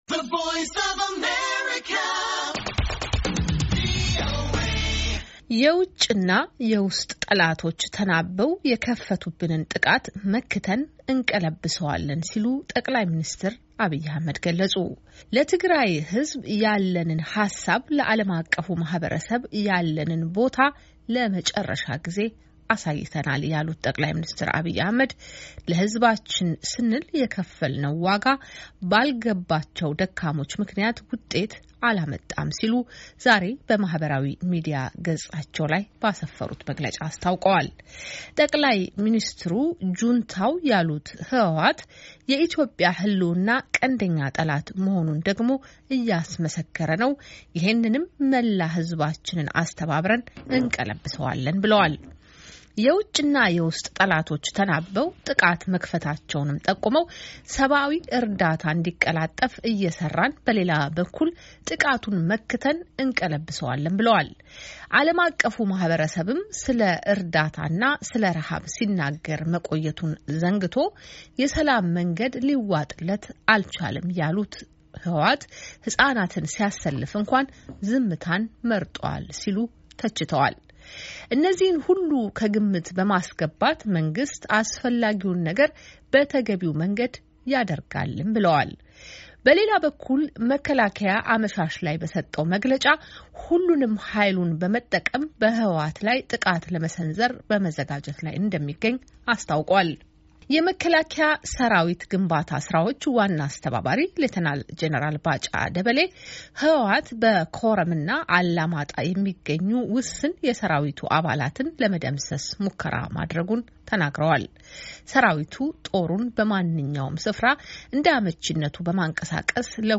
ዘገባ